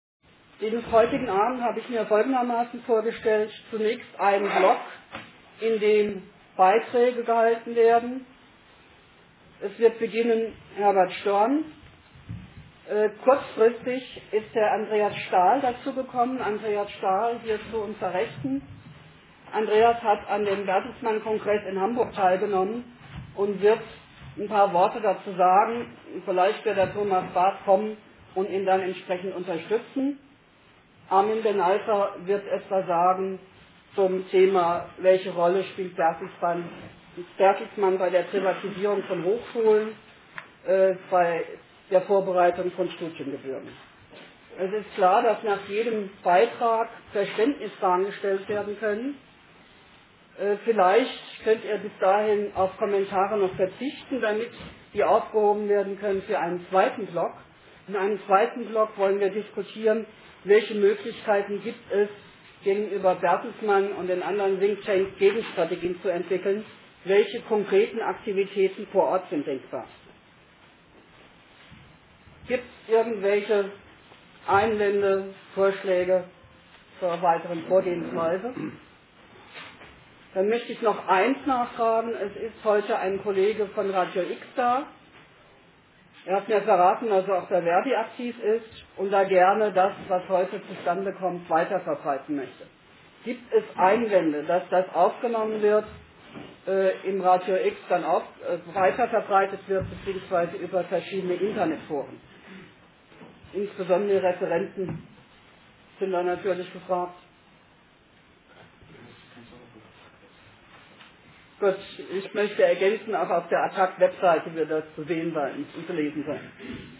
Attac-AG "Privatisierung Nein!", GEW BV-Ffm, ver.di FB 5 (Ffm), Jusos Ffm: hatten ins Gewerkschaftshaus eingeladen.
Über 80 Besucher erlebten einen interessanten Abend.